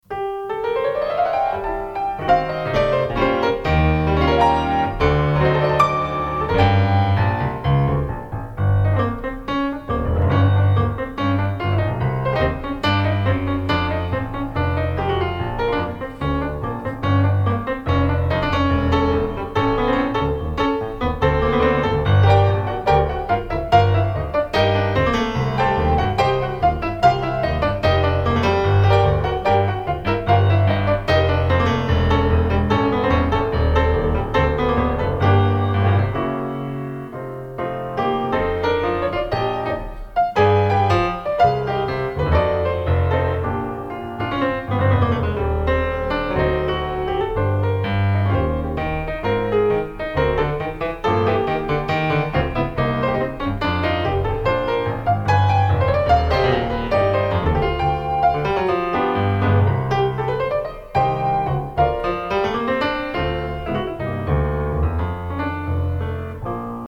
Plusieurs Cds  piano solo où domine l'improvisation:
piano solo 2003
tango fataliste et romantique